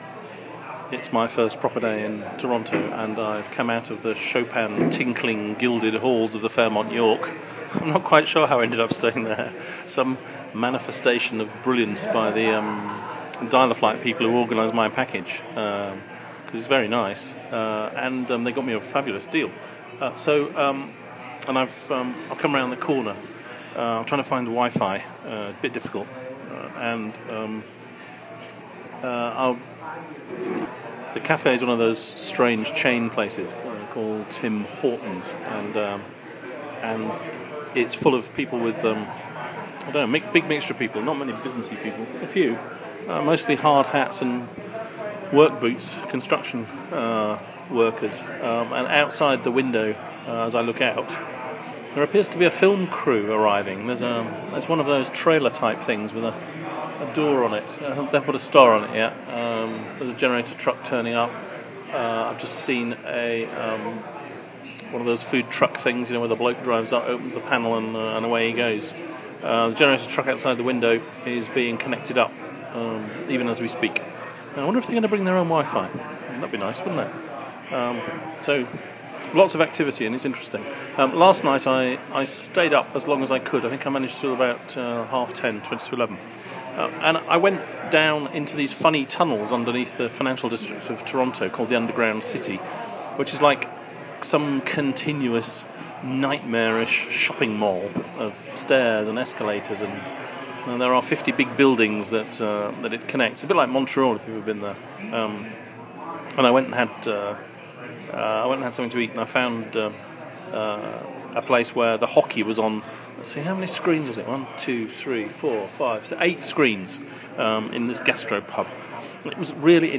Sports noise from mid-off